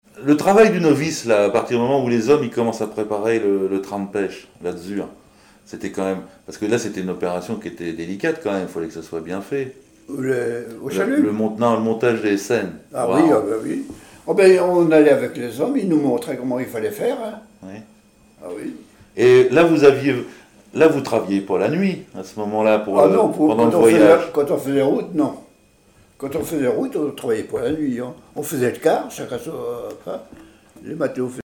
Témoignages sur la pêche sur les voiliers
Catégorie Témoignage